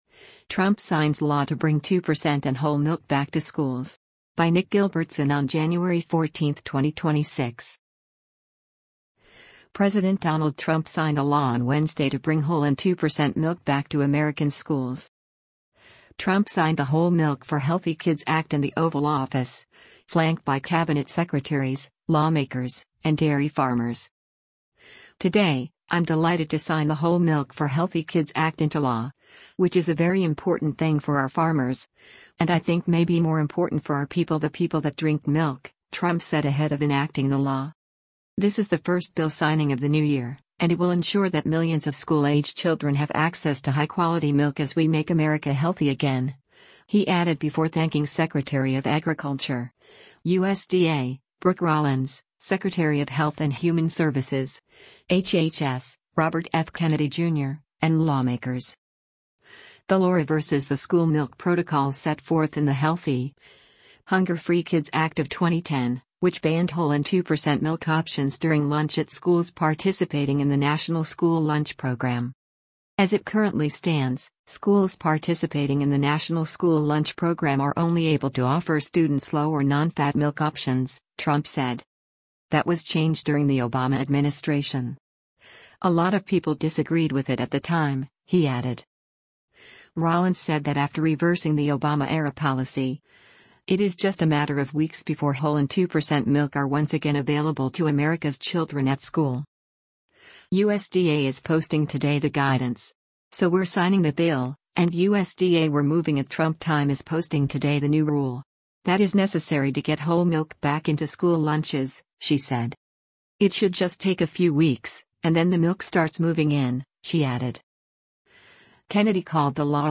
US President Donald Trump speaks during a signing ceremony in the Oval Office of the White
Trump signed the Whole Milk for Healthy Kids Act in the Oval Office, flanked by cabinet secretaries, lawmakers, and dairy farmers.